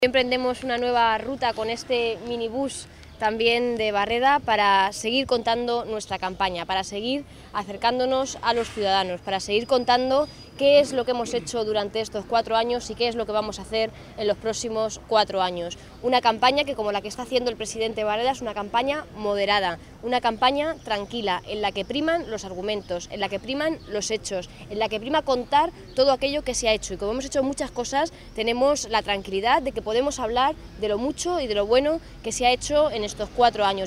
Isabel Rodríguez, atendiendo a los medios